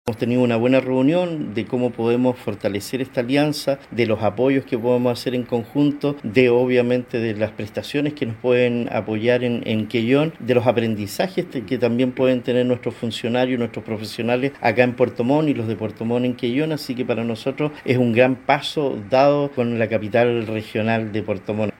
El alcalde de Quellón, Cristián Ojeda, precisó que este tipo de alianzas con municipios de mayor envergadura, se traducen en un gran apoyo para la Atención y de ayuda para las familias, sobre todo en la Isla de Chiloé.